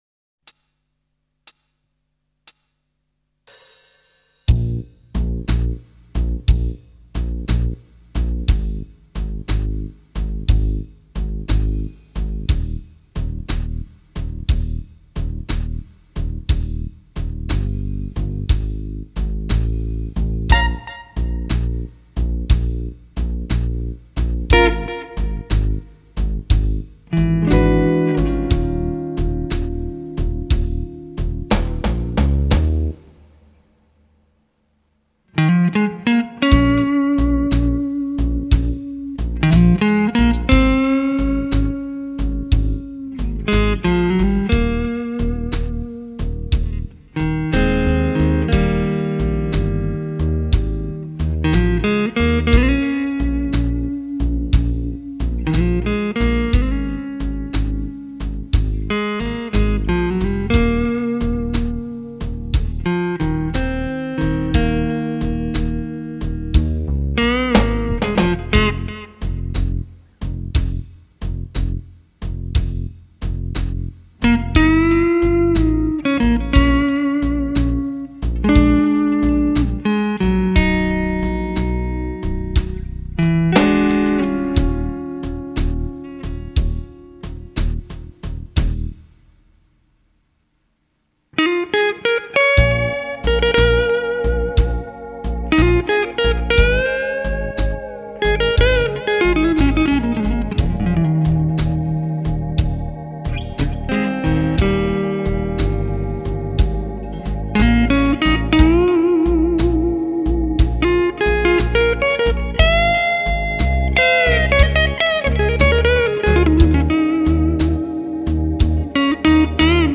Cordes neuves et identiques sur les 2 guitares (meme marque, meme
Precision: la Strat US etait equipee de micros Noiseless, ca change rien
Brush-Stratocaster.mp3